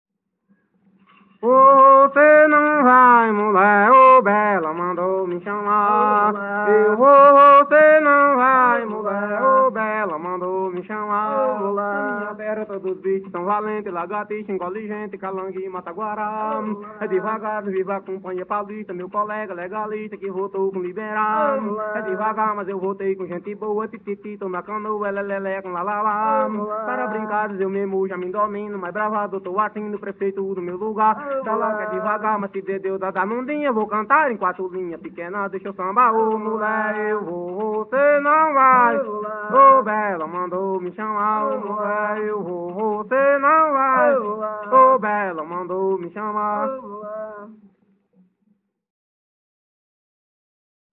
Coco embolada/galope - ""Eu vou, você não vai""